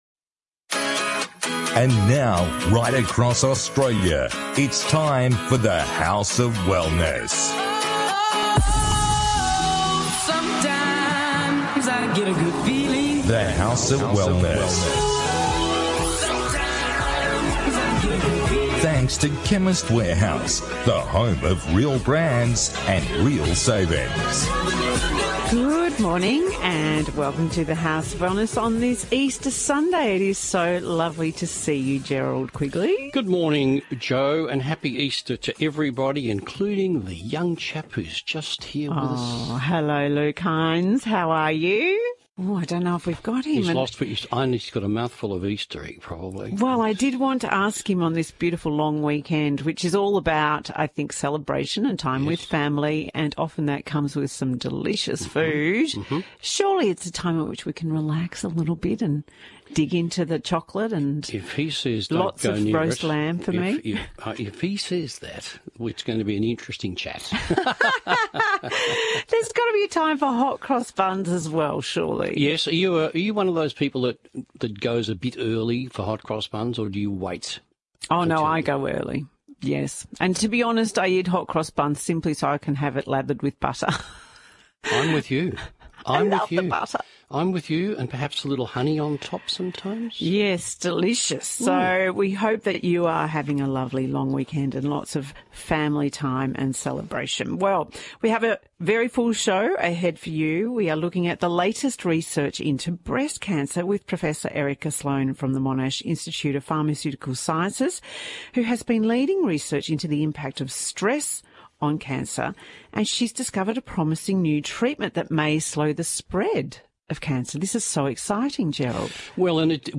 On this week’s The House of Wellness radio show the team discusses: